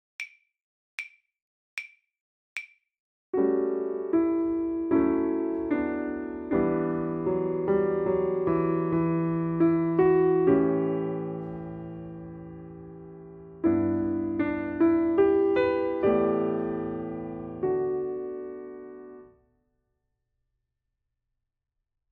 • Déchiffrage avec piano (Texte, Rythme et Intervalle)
91 - Page 42 - déchiffrage 1 - piano seul